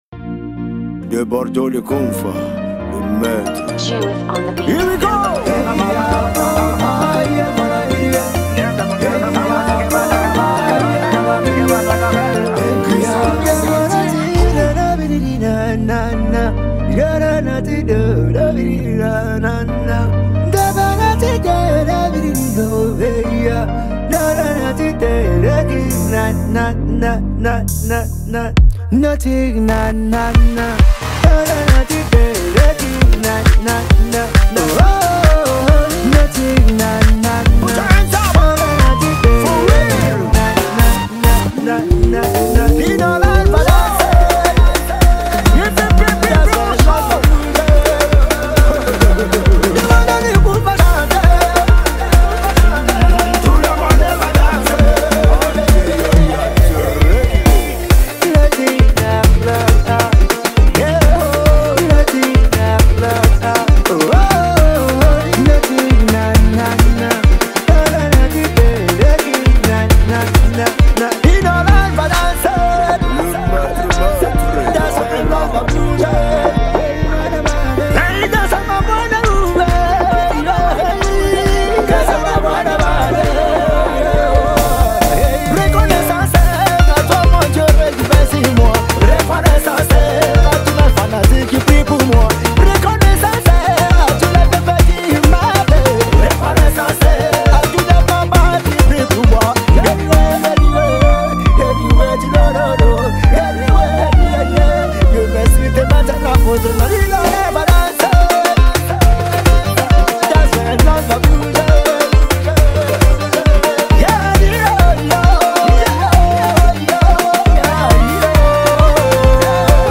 | Coupé décalé